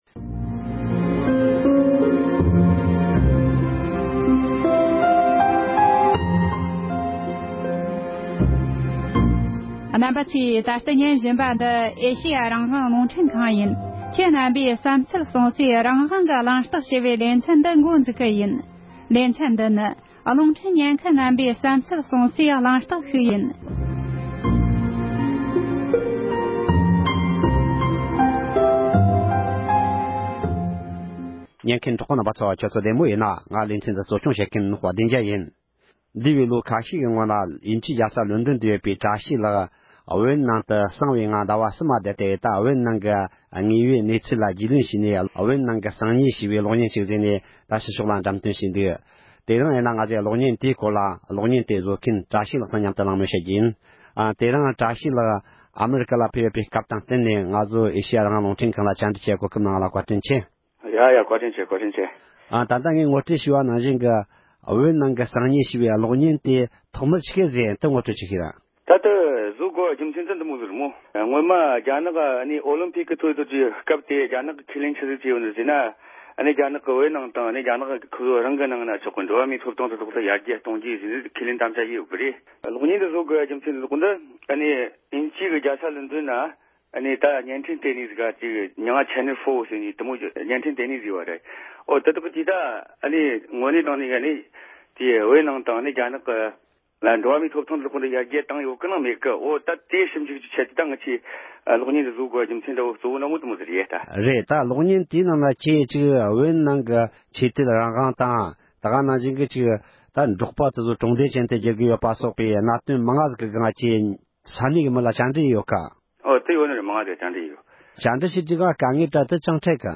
དེ་བཞིན་གློག་བརྙན་དེའི་ནང་བོད་ནང་གི་བོད་མི་ཁག་ཅིག་གིས་ད་ལྟའི་གནས་སྟངས་འགྲེལ་བརྗོད་གནང་བར་གསན་རོགས་གནོངས༎